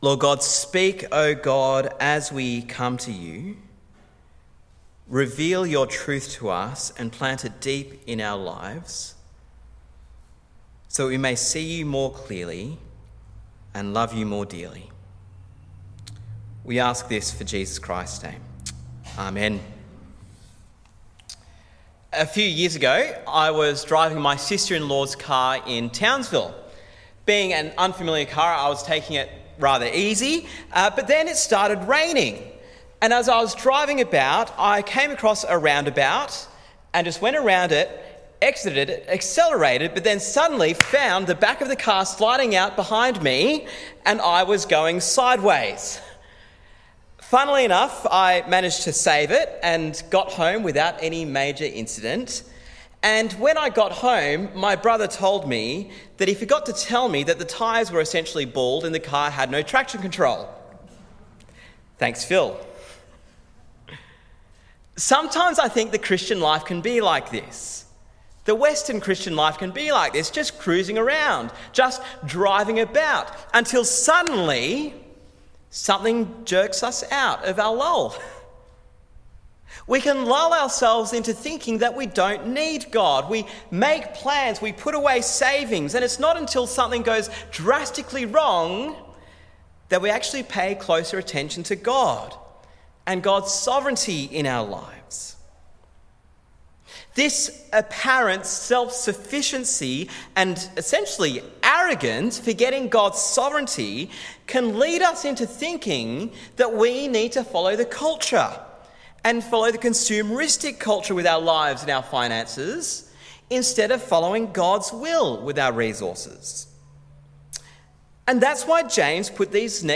Sermon on James 4:11-5:8